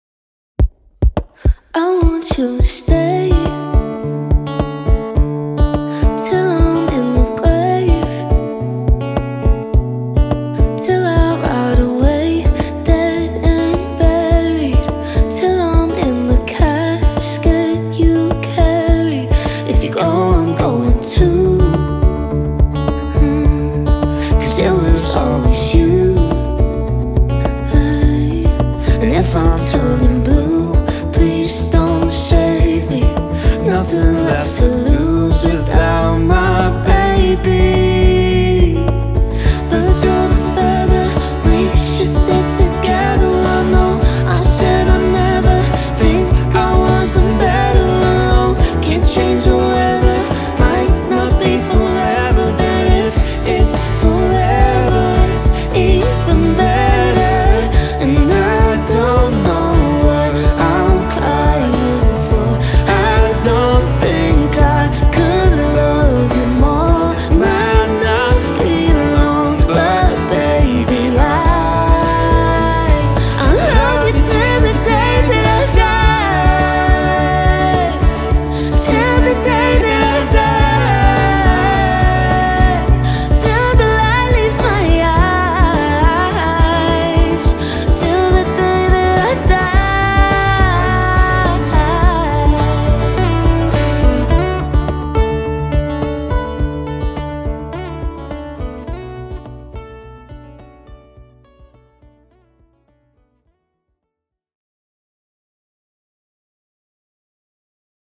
Guitars | Loops | Vocals | DJ | MC